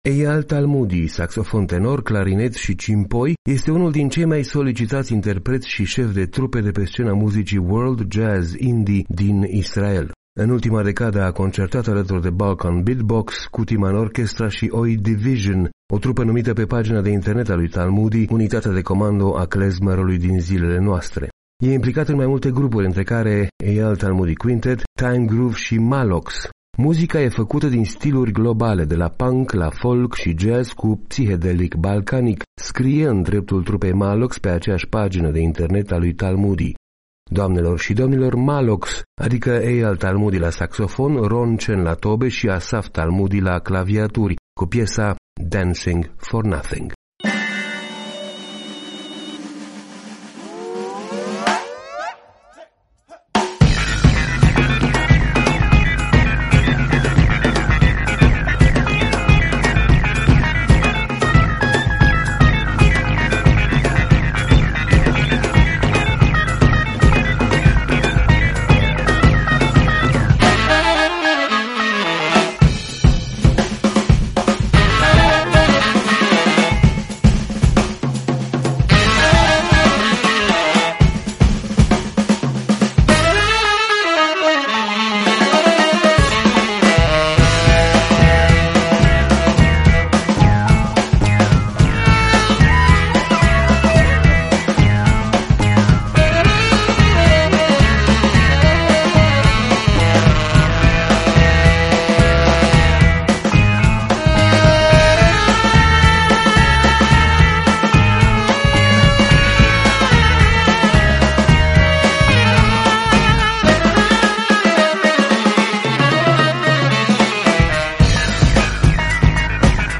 saxofon
de la punk la folk și jazz, cu psihedelic balcanic”
claviaturi